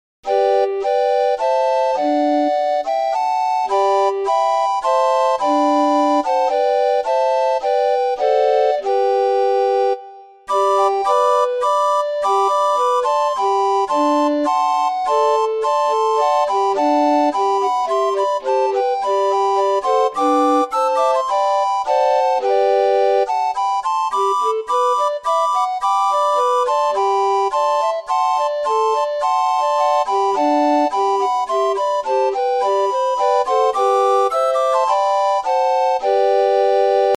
Books for 16-notes Oldfellow Organ,